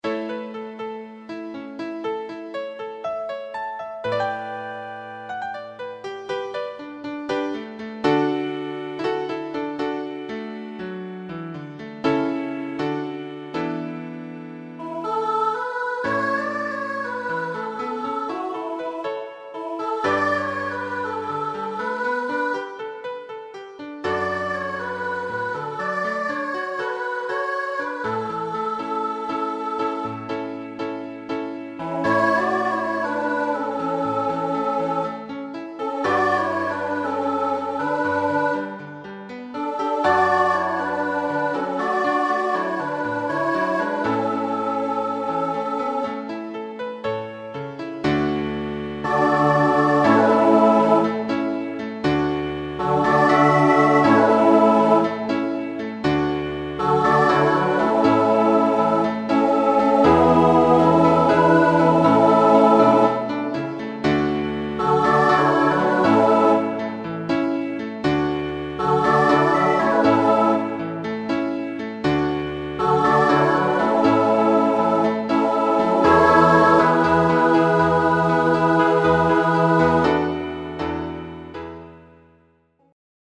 SATB choir, piano and guitar
SATB choir with piano accompaniment Original